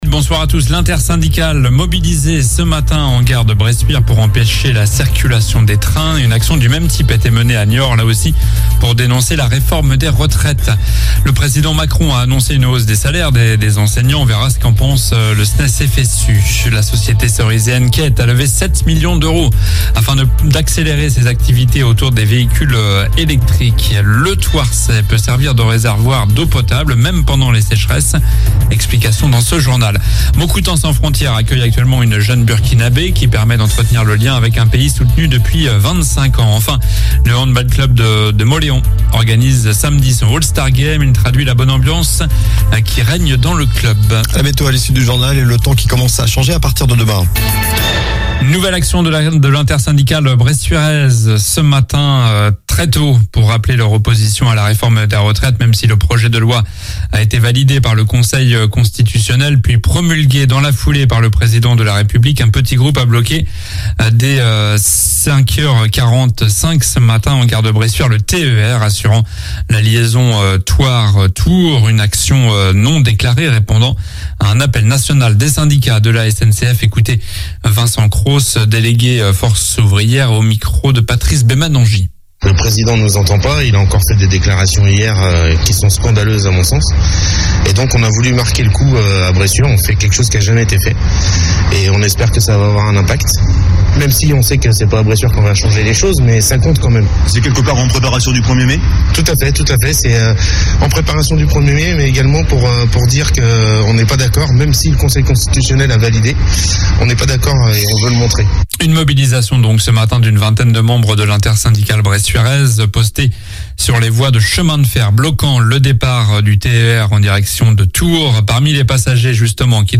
Journal du jeudi 20 avril (soir)